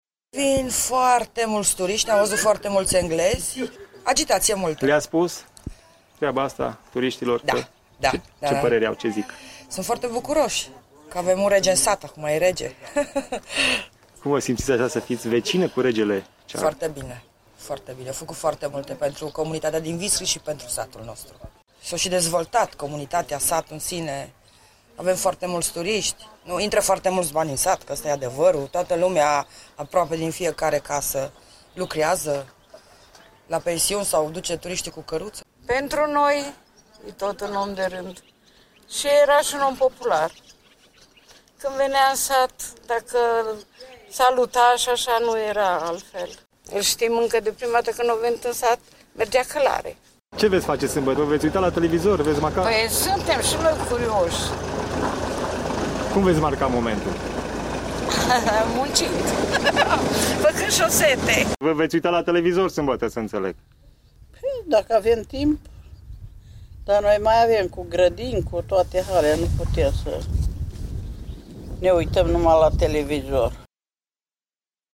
Localnicii au amintiri frumoase legate de Majestatea Sa și spun că vor urmări la televizor, dacă vor avea timp, ceremonia încoronării:
vox-viscri.mp3